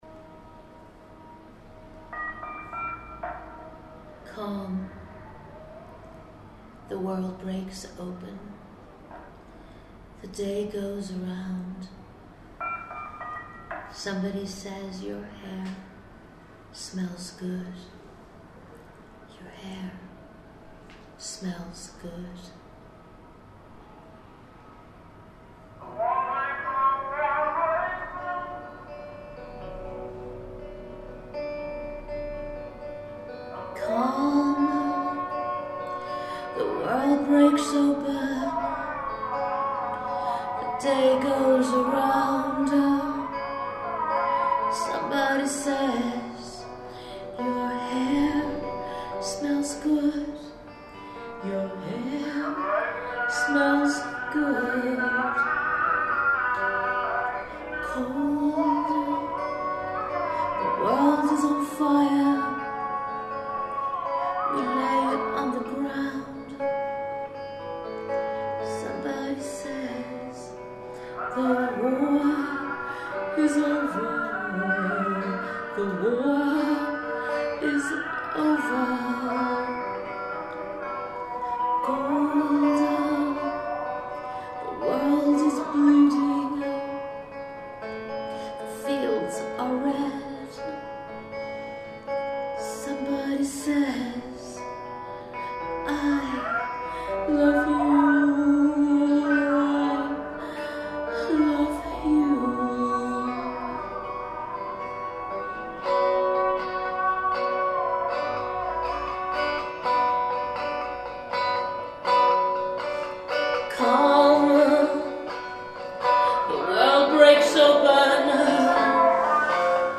Vocals
recorded in Izmir, Turkey